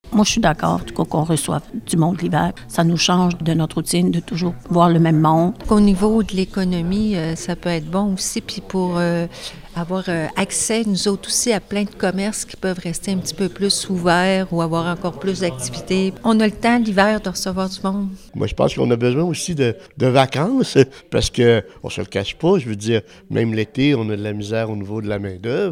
Voici le témoignage de trois participants: